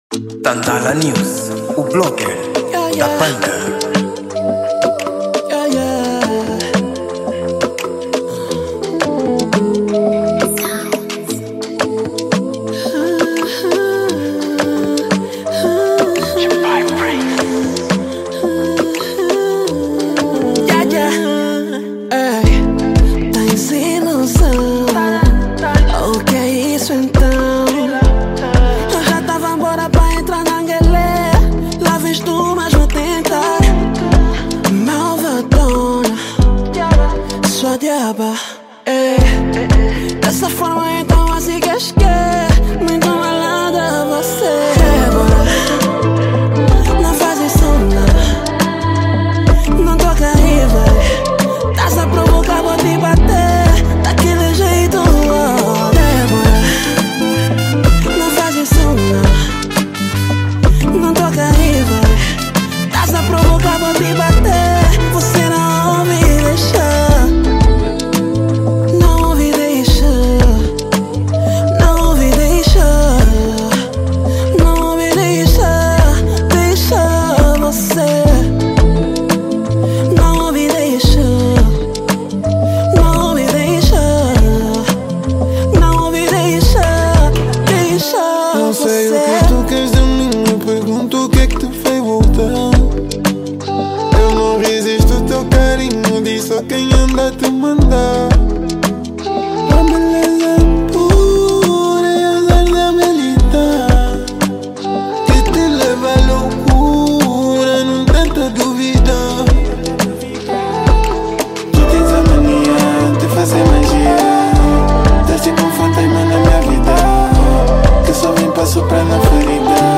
Gênero: Trap Soul